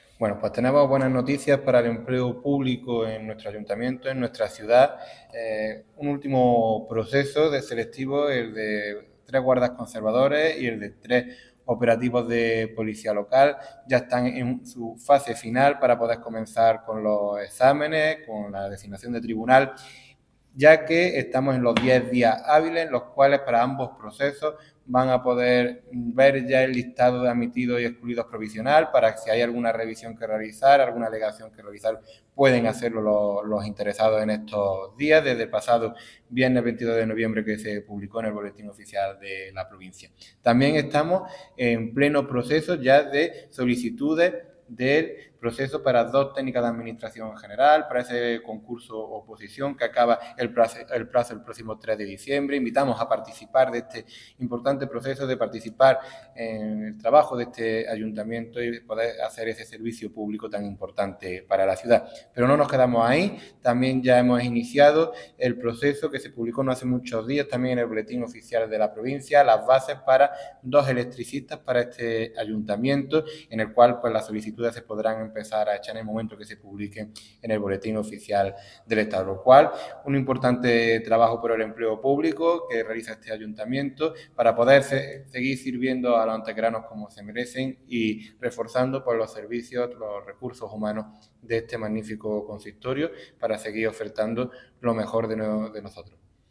El teniente de alcalde delegado de Personal del Ayuntamiento de Antequera, Antonio García Mendoza, informa del desarrollo de varios procesos selectivos de empleados públicos dentro del consistorio para un total de 10 puestos: 3 guardas conservadores, 3 servicios operativos de Policía Local, 2 técnicos de administración general y 2 electricistas.
Cortes de voz